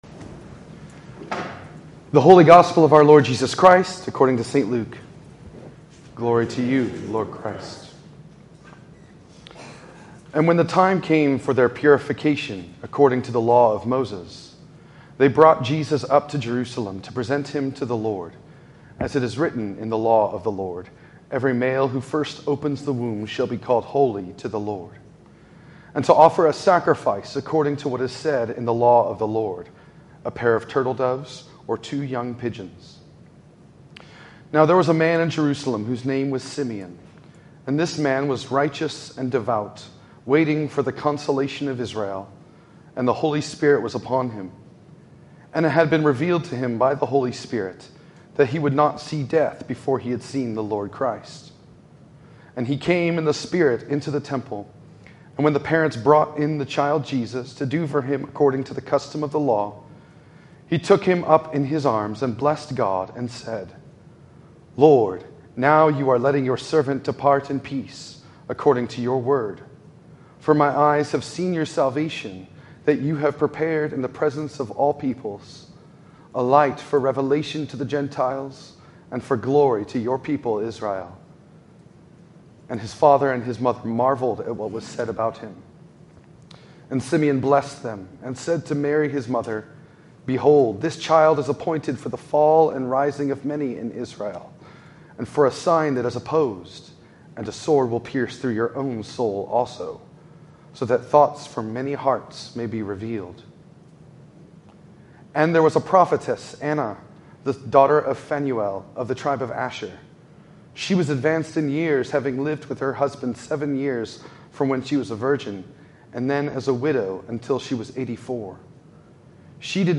In this sermon on the second Sunday of Christmas